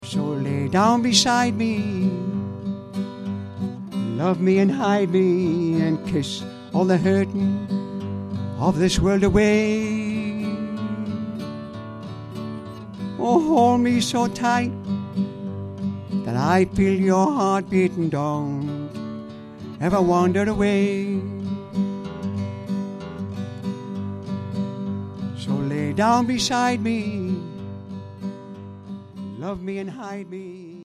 In the second half he gave us Slim Whitman's 'I'm A Fool' along with a bit of yodelling!
It's all at Ashington Folk Club!